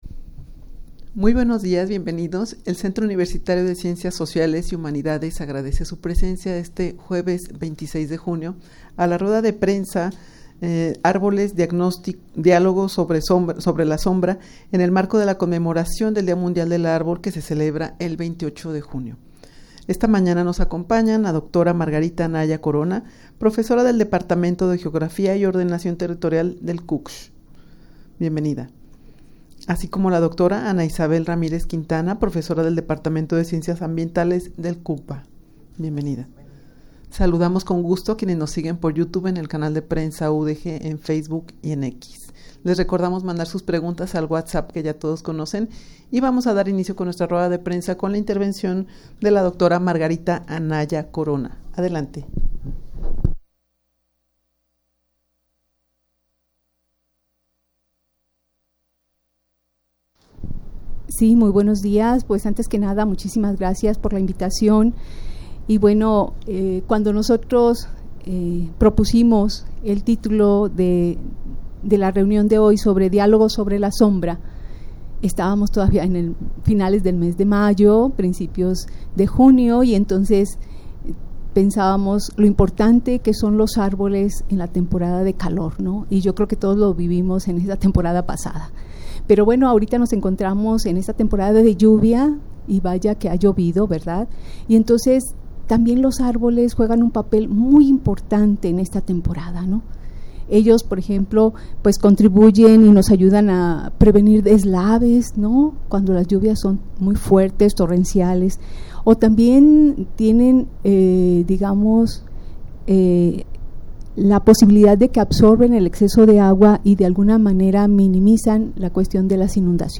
Audio de la Rueda de Prensa
rueda-de-prensa-arboles-dialogos-sobre-la-sombra-en-el-marco-de-la-conmemoracion-del-dia-mundial-del-arbol.mp3